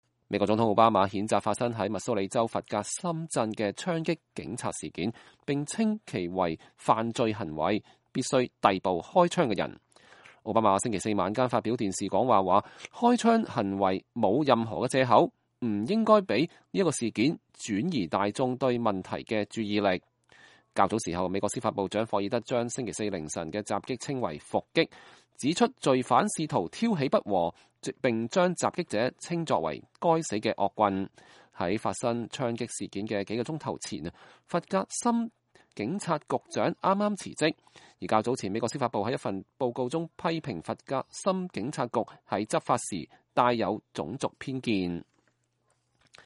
美國總統奧巴馬譴責發生在密蘇里州弗格森鎮的槍擊警察事件，並稱其為犯罪行為，“必須逮捕”開槍的人。奧巴馬星期四晚間發表電視講話說，開槍行為沒有任何理由，不應讓這一事件轉移大眾對問題的注意力。